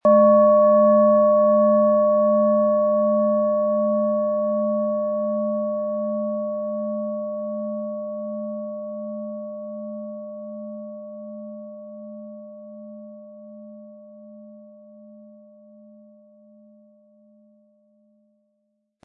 Die Planetenklangschale Neptun ist handgefertigt aus Bronze.
Durch die traditionsreiche Fertigung hat die Schale vielmehr diesen kraftvollen Ton und das tiefe, innere Berühren der traditionellen Handarbeit.
MaterialBronze